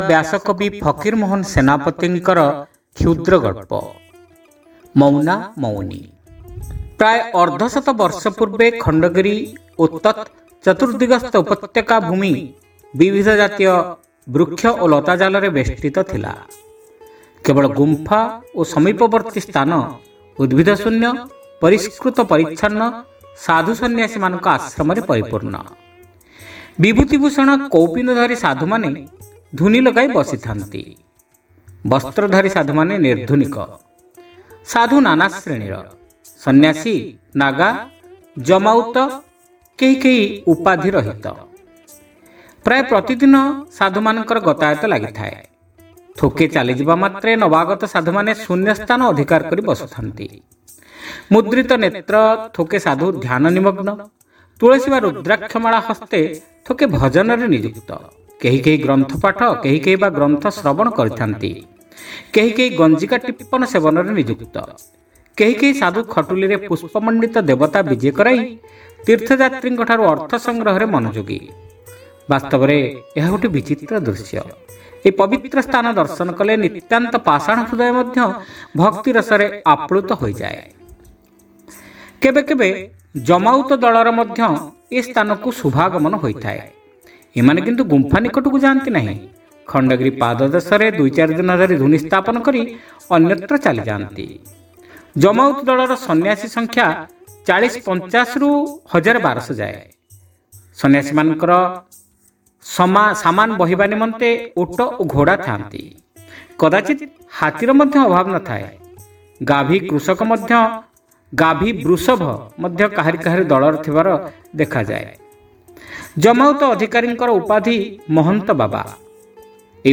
Audio Story : Mouna Mouni (Part-1)